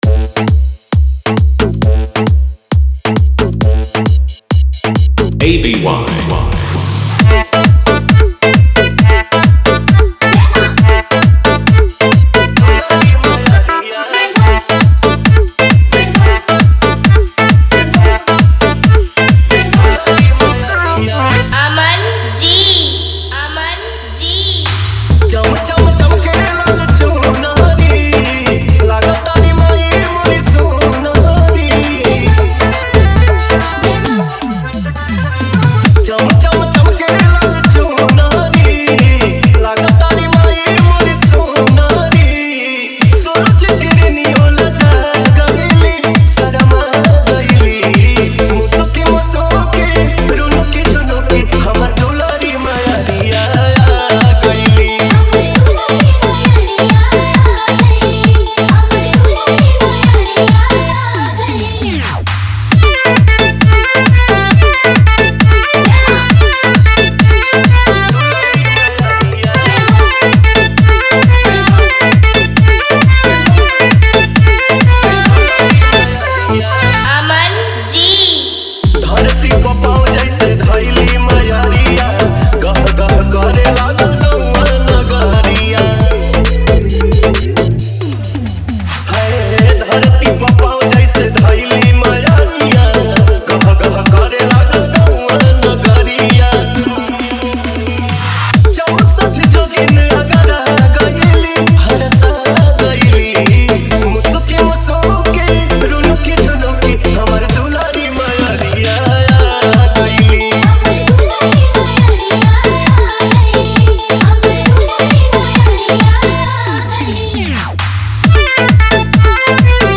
Navratri Dj Remix Song Play Pause Vol + Vol